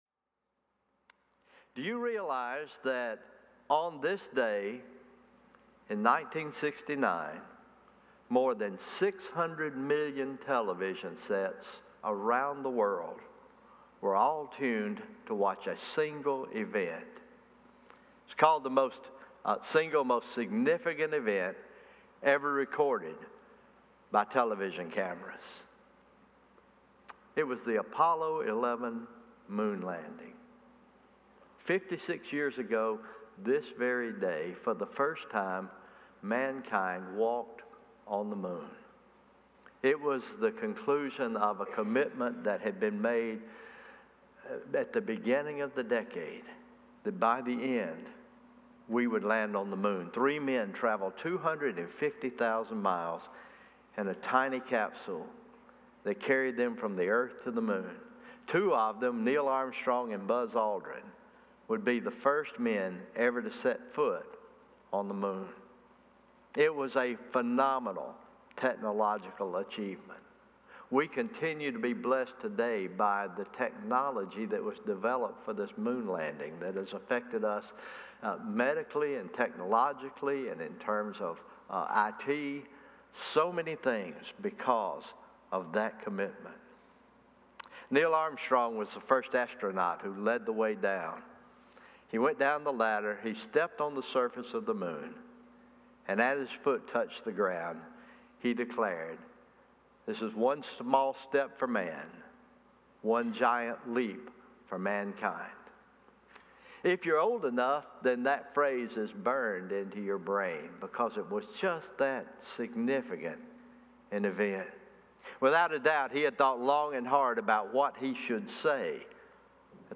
Morning Worship
Communion Meditation Copy